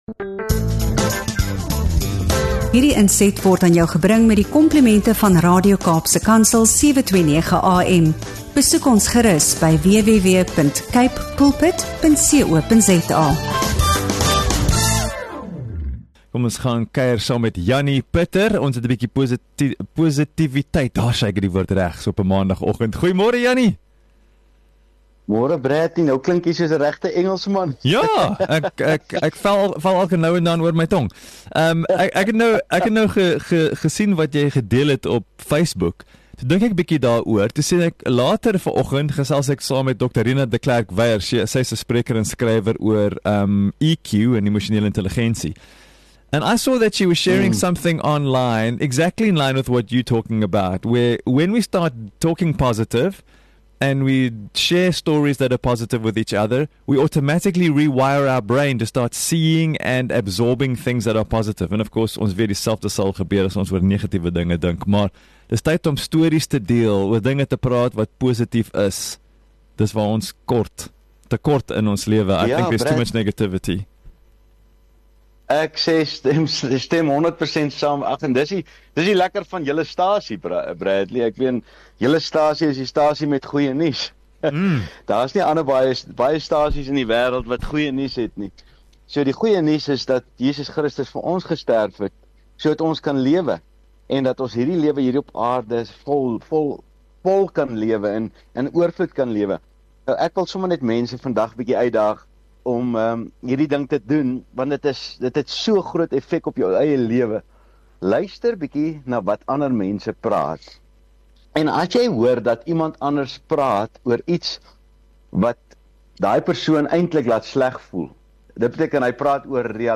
’n Motiverende en opbouende gesprek vir enigiemand wat voluit wil leef en ander wil inspireer.